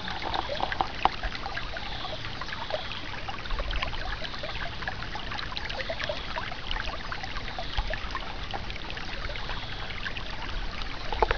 Abb. 04: Hellgrünes Laub und das Murmeln eines Baches, Tondatei: (Wave-Datei  125 kB)